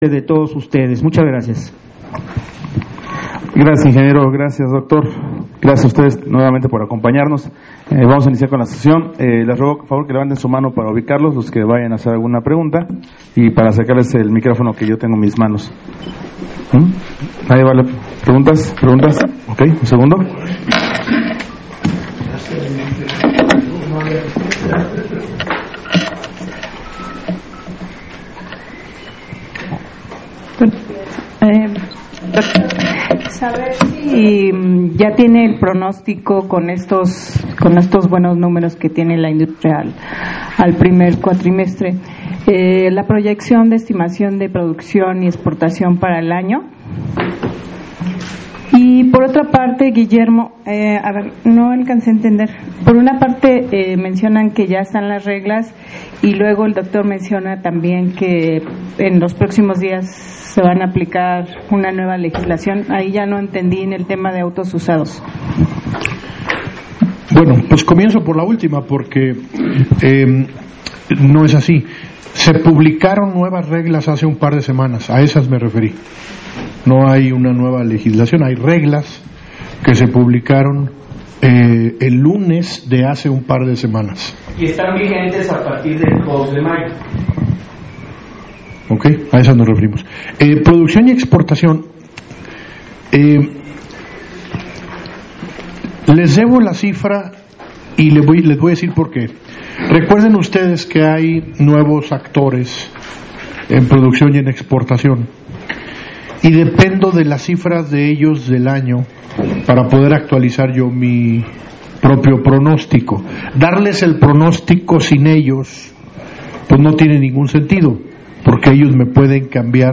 audio de la conferencia